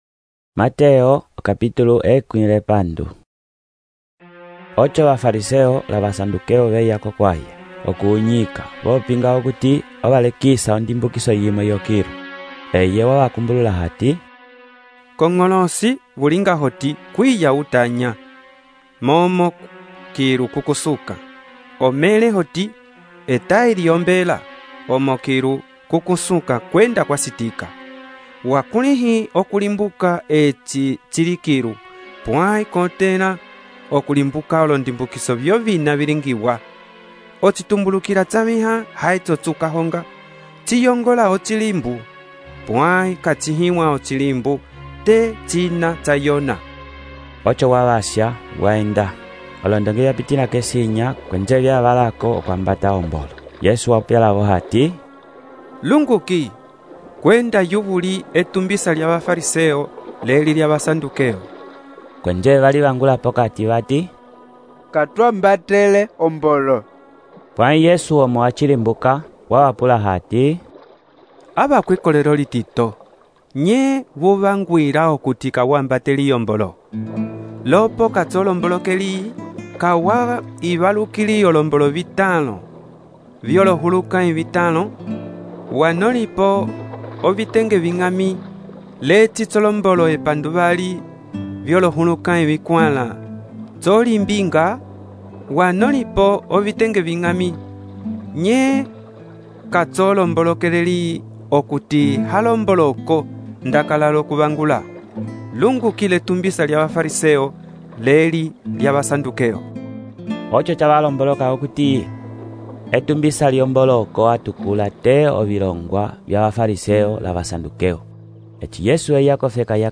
texto e narração , Mateus, capítulo 16